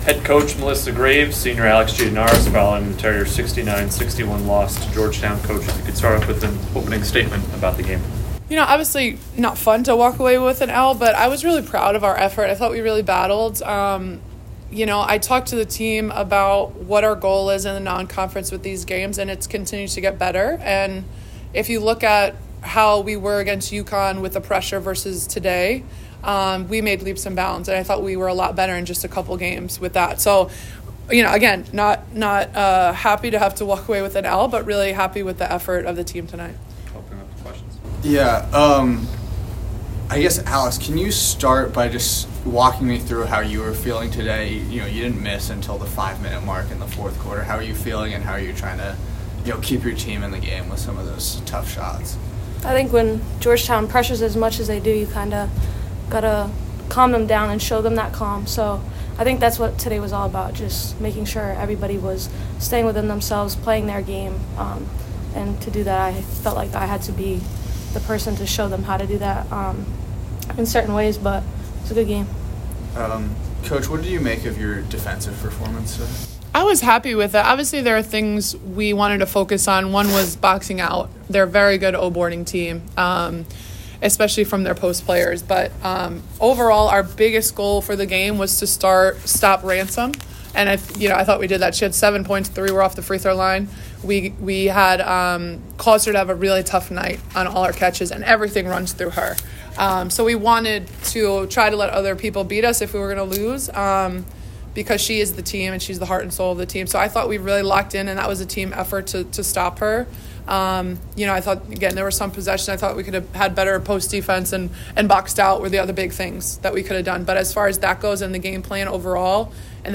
Women's Basketball / Georgetown Postgame Press Conference (11-27-24)